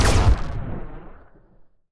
Erekir unit SFX
shockBlast.ogg